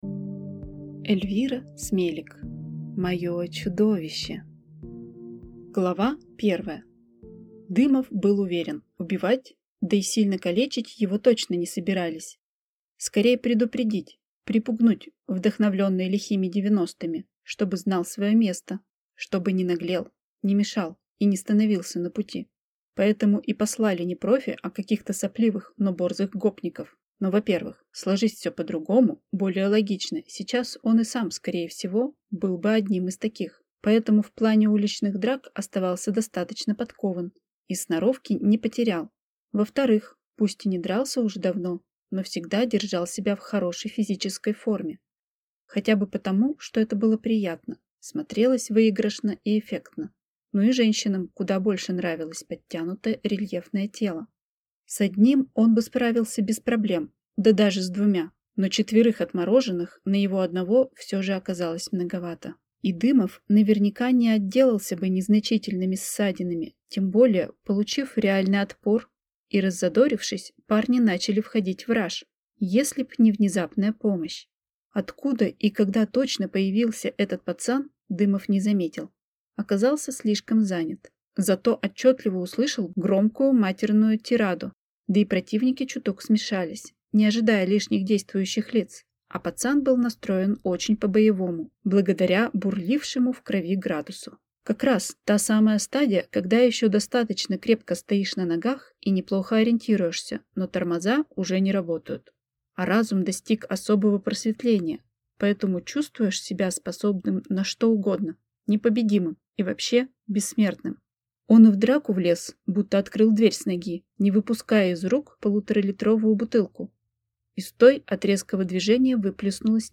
Аудиокнига Моё чудовище | Библиотека аудиокниг